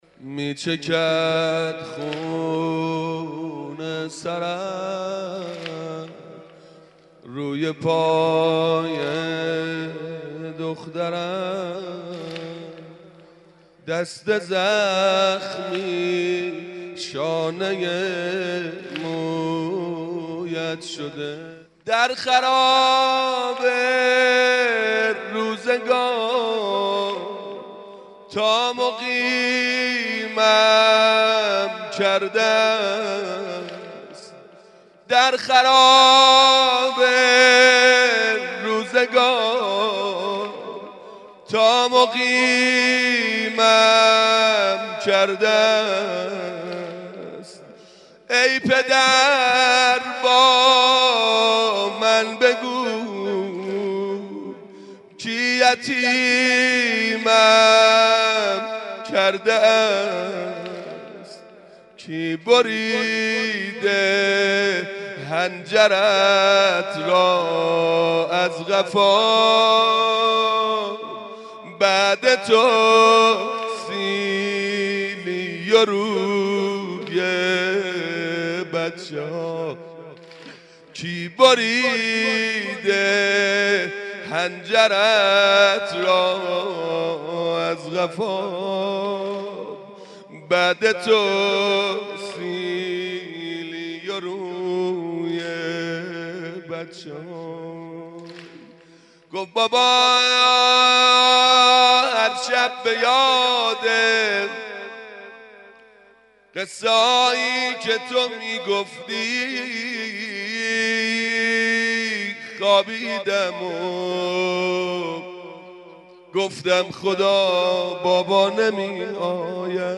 شب هفتم رمضان95